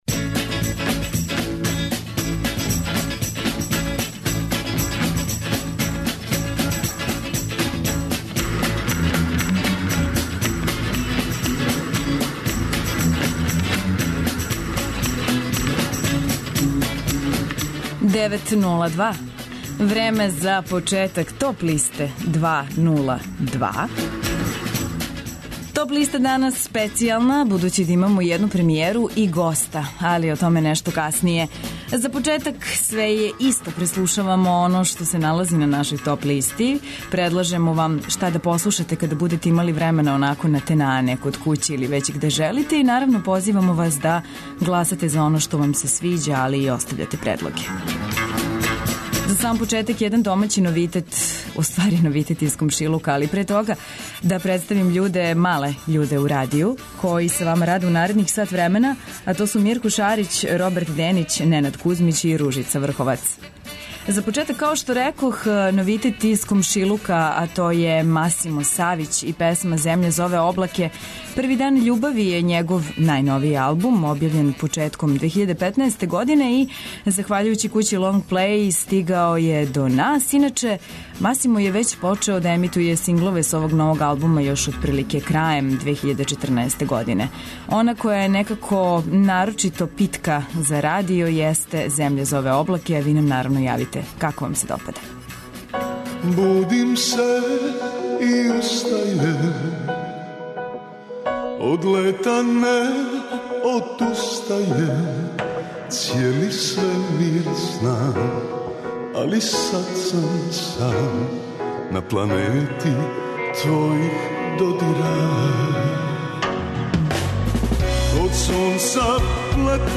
Поред тога што ће премијерно емитовати нови сингл, Гиле ће најавити и рођенданске концерте у Београду и Бечу.